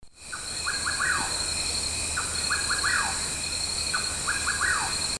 Rufous Nightjar (Antrostomus rufus)
Life Stage: Adult
Location or protected area: Parque Nacional Chaco
Condition: Wild
Certainty: Recorded vocal